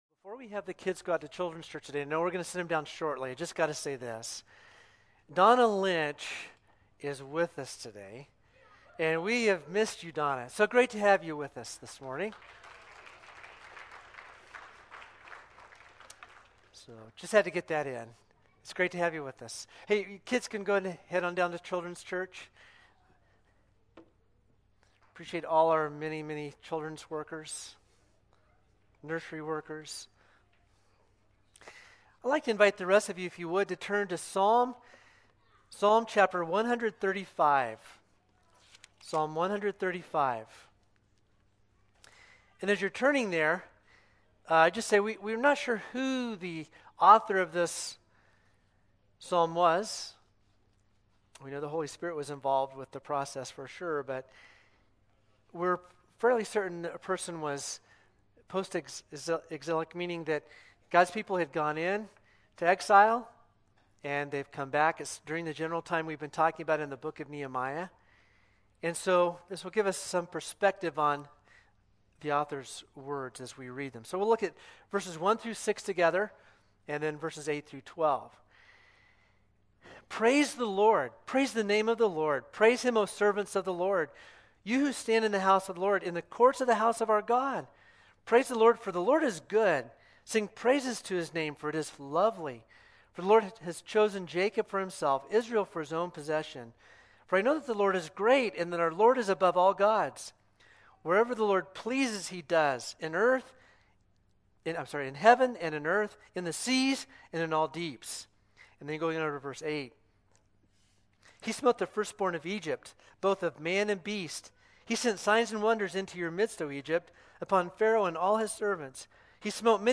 5_29-22-sermon.mp3